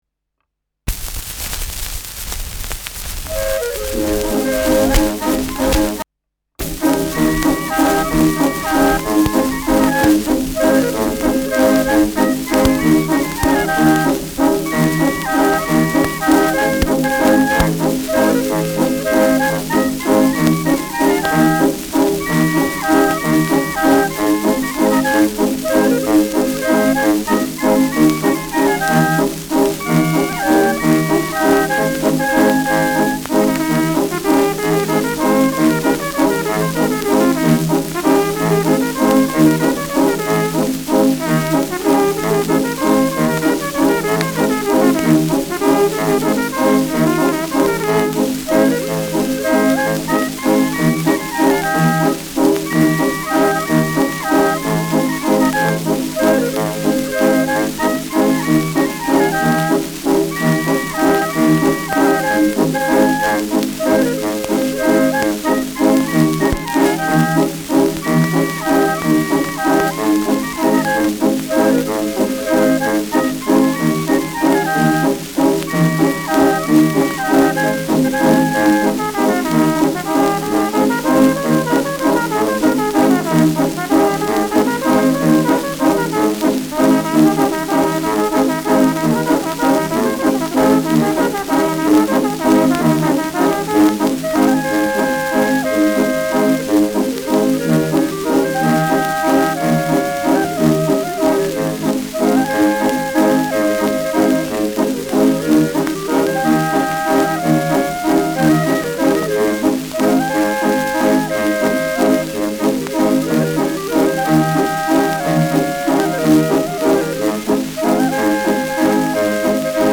Schellackplatte
im ersten Takt „Hängen“ : präsentes Rauschen : zu Beginn Knacken : leichtes Leiern : abgespielt : gelegentliches Knacken
[München] (Aufnahmeort)